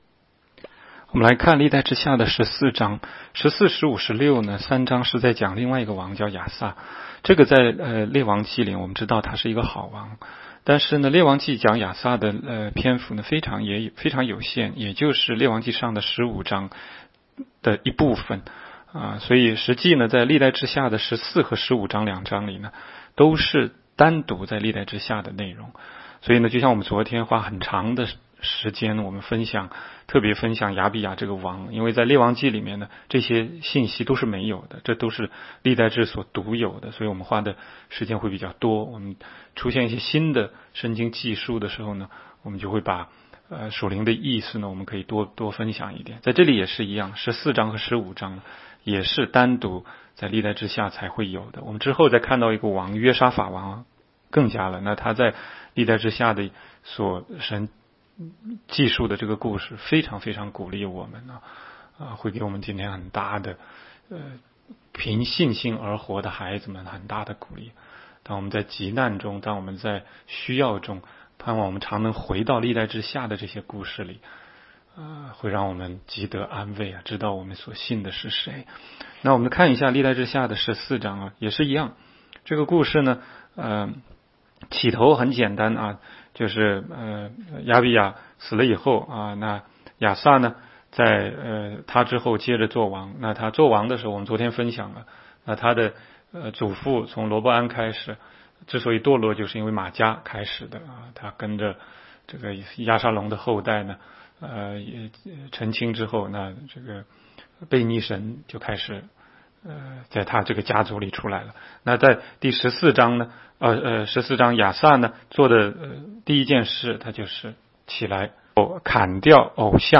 16街讲道录音 - 每日读经-《历代志下》14章